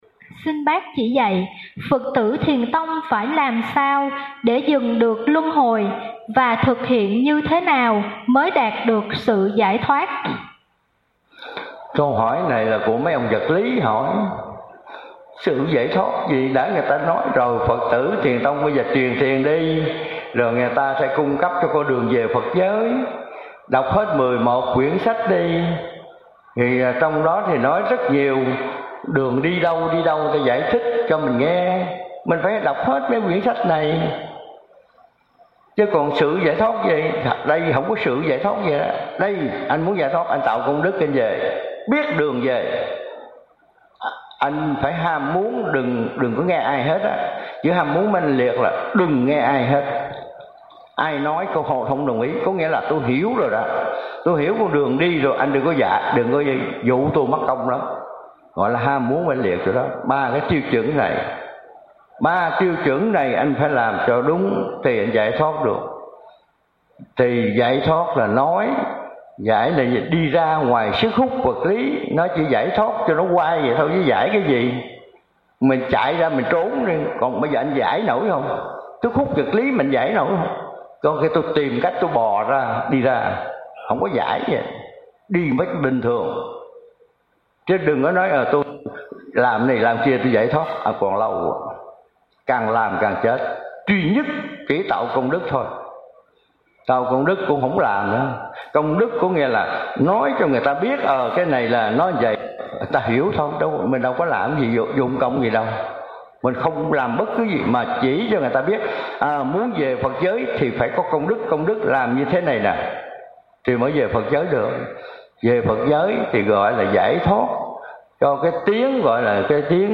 Trò hỏi:
Thầy trả lời: